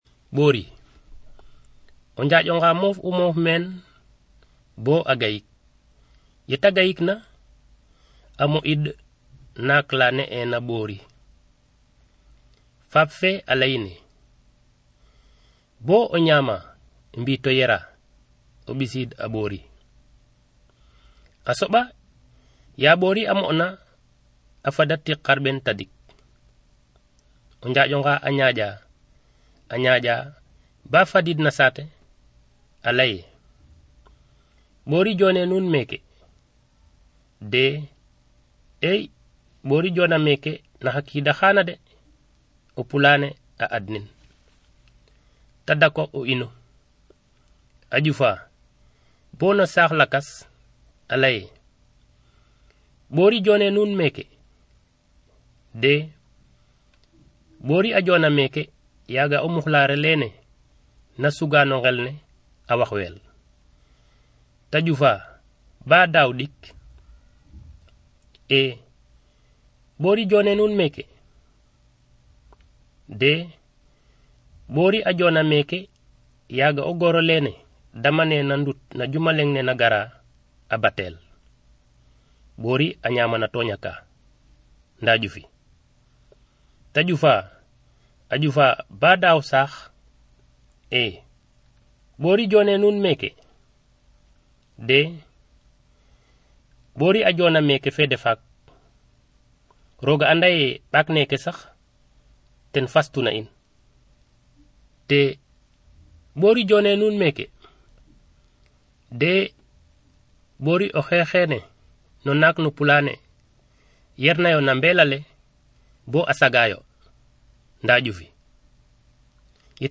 Un conte